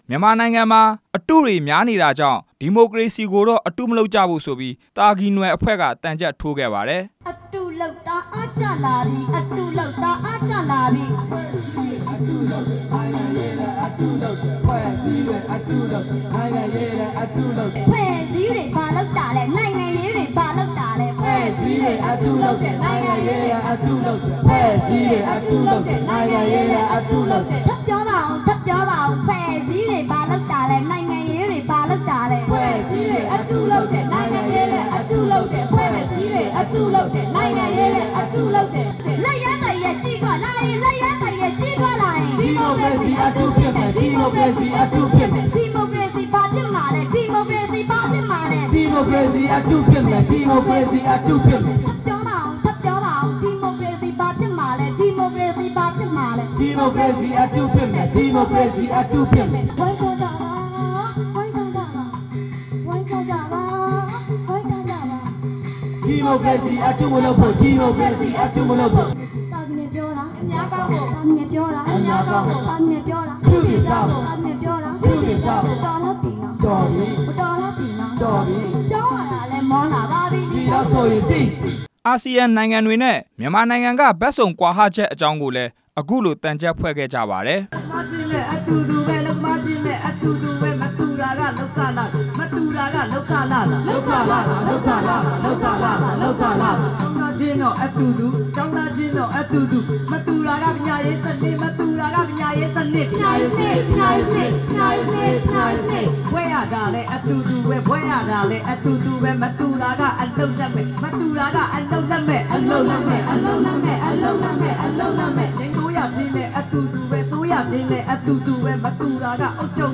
မနေ့က သြင်္ကန်အကြက်နေ့ညမှာ သာကီနွယ်သံချပ်အဖွဲ့က ရန်ကုန်မြို့ သင်္ဃန်းကျွန်းမြို့နယ် ရွှေစင်မဏ္ဍပ်မှာ ဖျော်ဖြေခဲ့ကြပါတယ်။ ရွှေစင်မဏ္ဍပ်ဟာ ရေကစားမဏ္ဍပ်သီးသန့်မဟုတ်ပဲ သံချပ်တွေ ယှဉ်ပြိုင်ကြတဲ့ မဏ္ဍပ်ဖြစ်ပါတယ်။